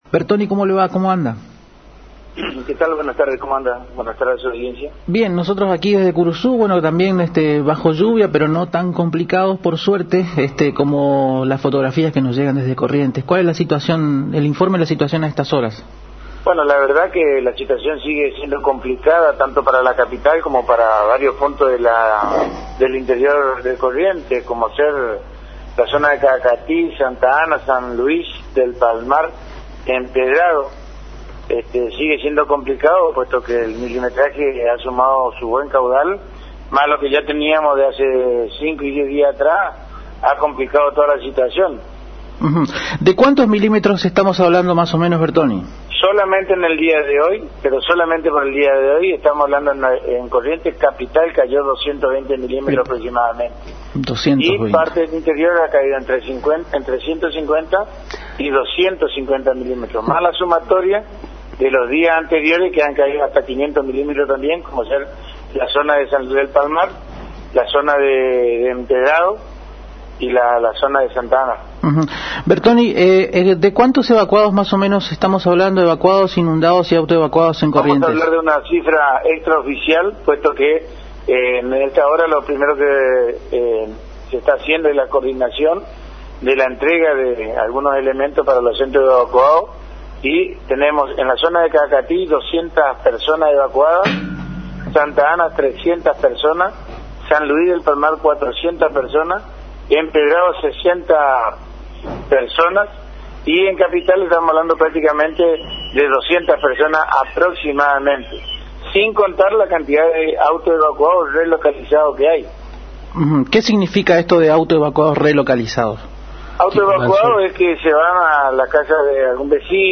En contacto con la AM 970 Radio Guarani